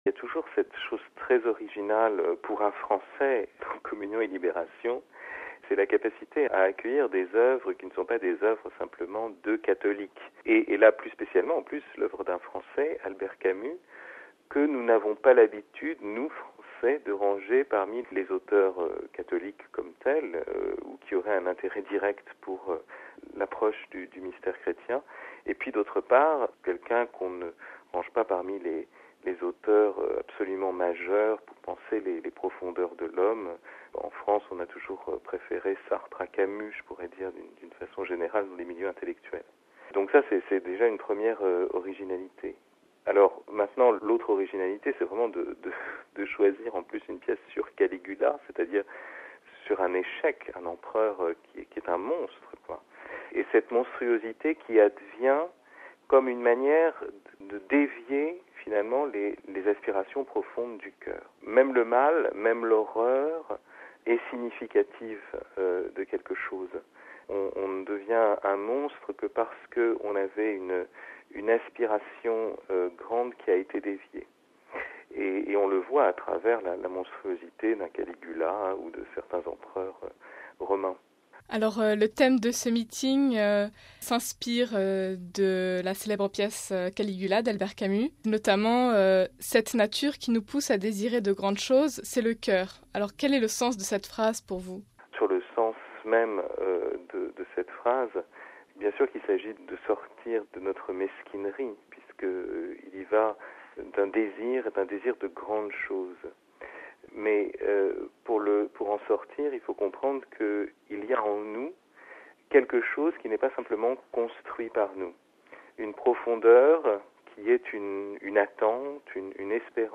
Le philosophe et écrivain français Fabrice Hadjadj interviendra samedi à propos du dernier livre de Don Luigi Giussani, fondateur du mouvement, mort il y a 5 ans.